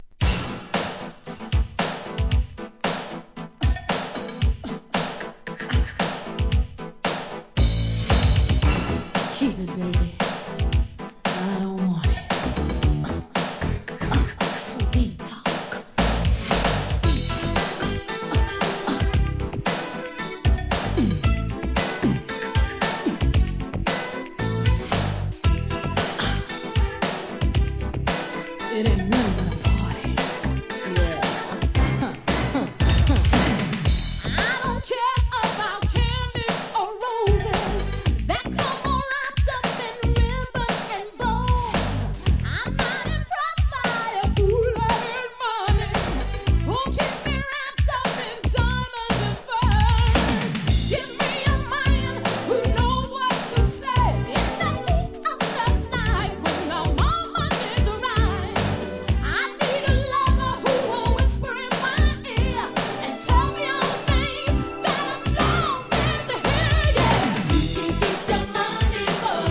NEW JACK SWING